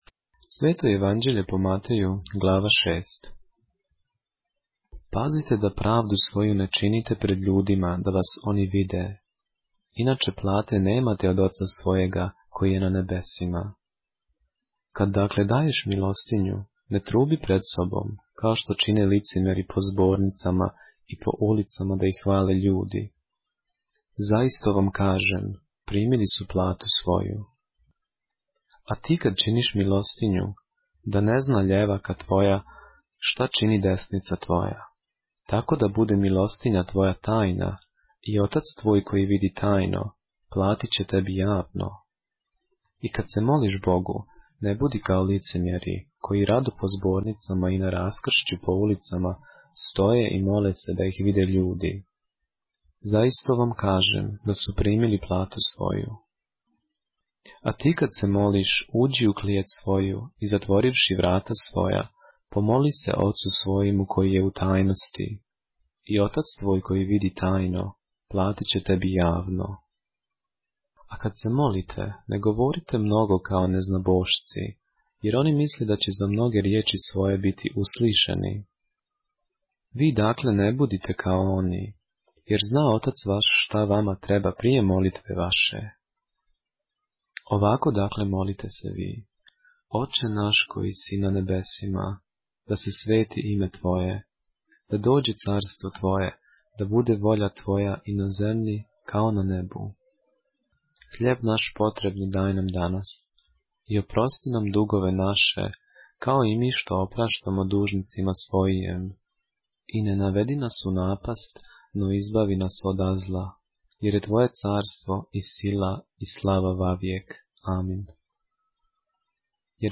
поглавље српске Библије - са аудио нарације - Matthew, chapter 6 of the Holy Bible in the Serbian language